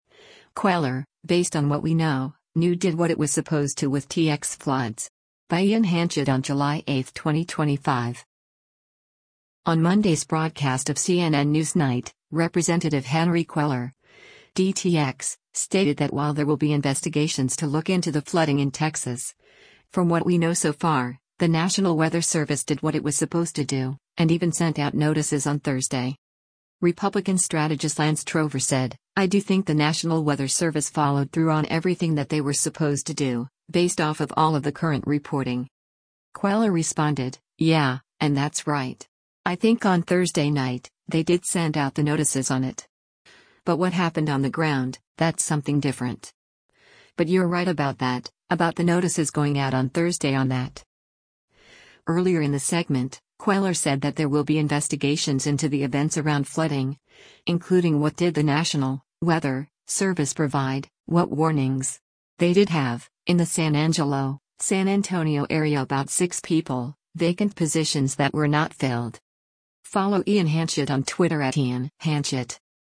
On Monday’s broadcast of “CNN NewsNight,” Rep. Henry Cuellar (D-TX) stated that while there will be investigations to look into the flooding in Texas, from what we know so far, the National Weather Service did what it was supposed to do, and even sent out notices on Thursday.